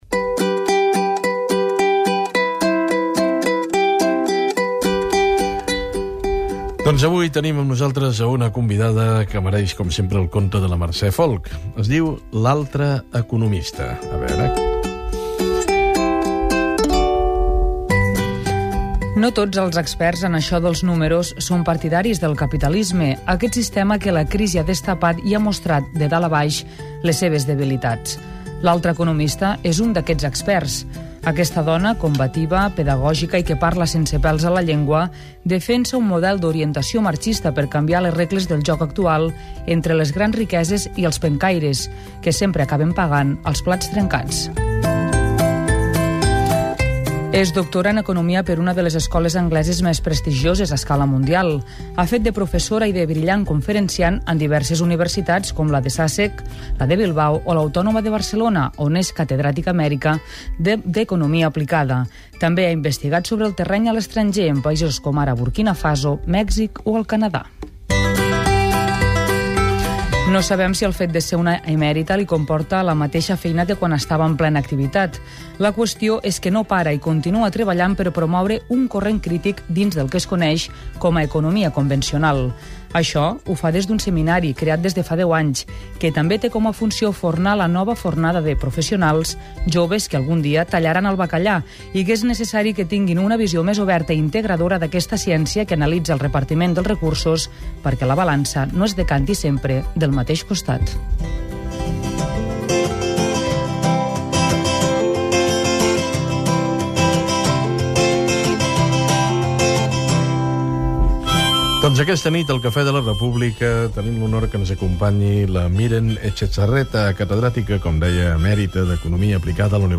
El Cafè de la República entrevista